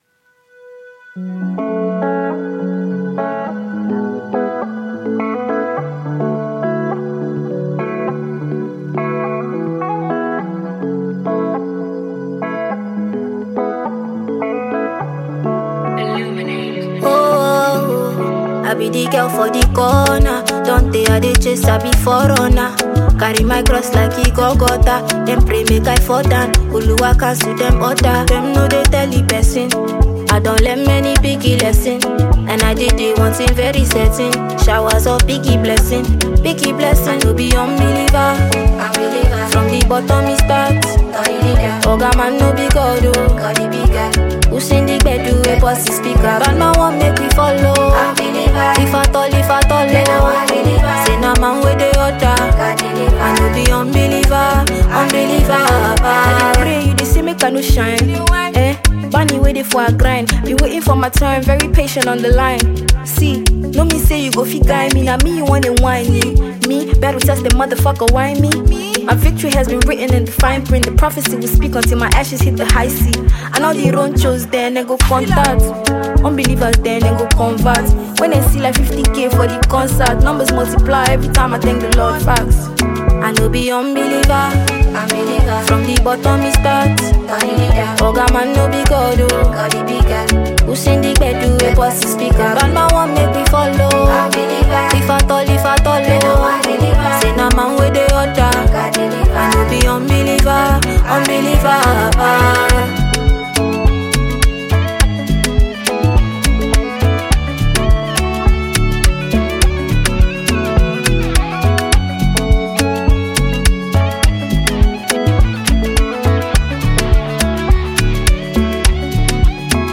Fast-rising Nigerian female singer